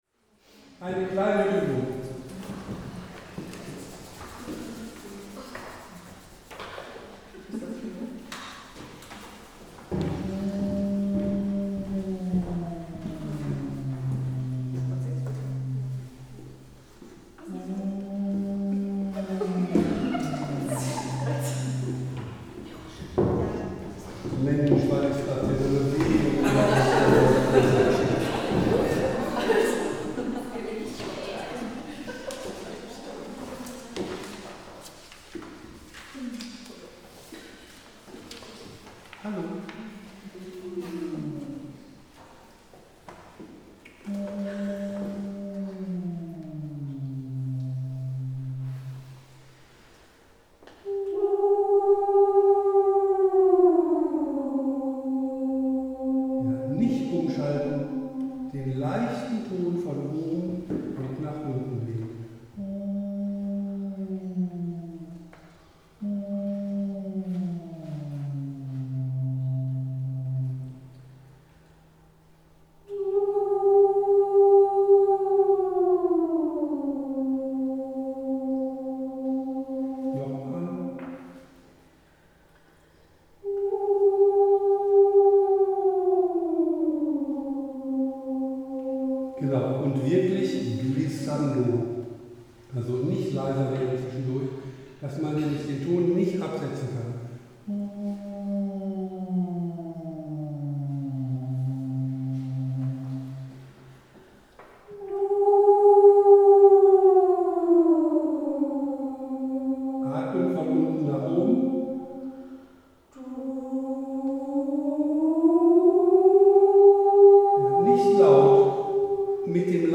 wichtige Übung zum Lagenausgleich - Konzertchor Sång
Den-tiefen-Frieden-Uebung-zum-Lagenausgleich.mp3